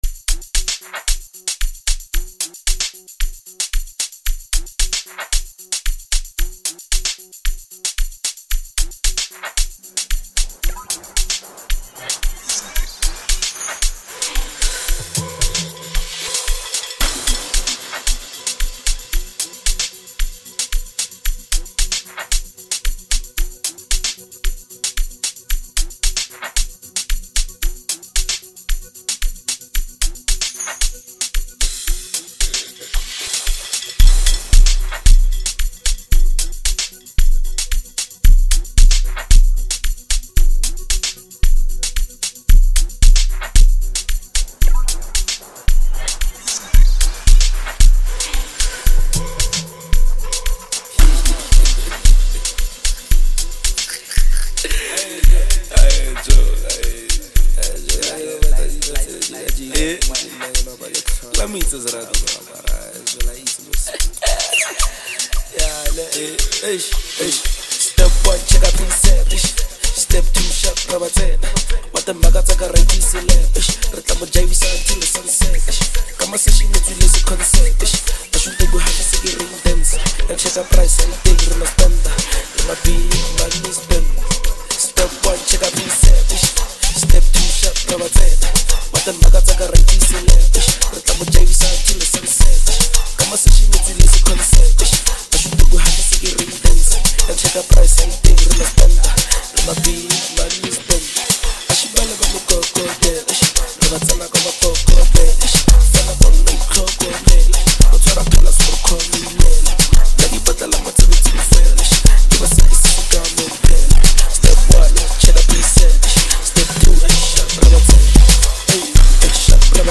Home » Amapiano » Lekompo